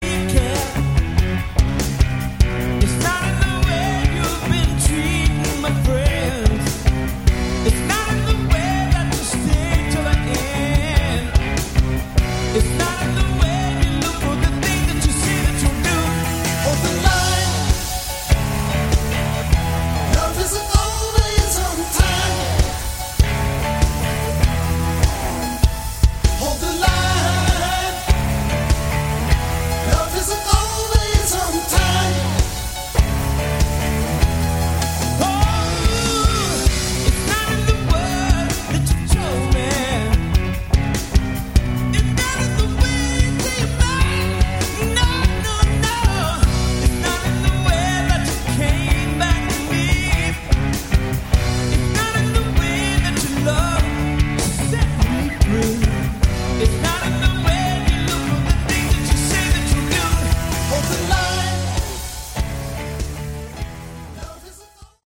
Category: AOR
live